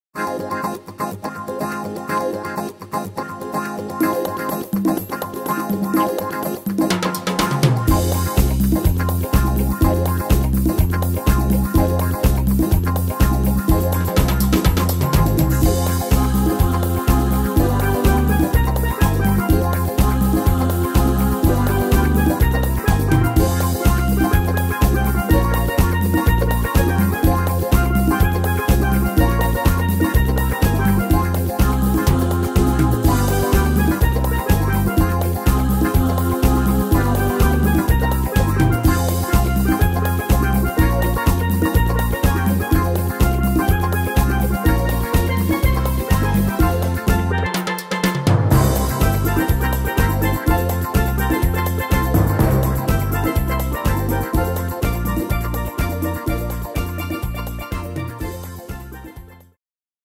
Tempo: 124 / Tonart: F-moll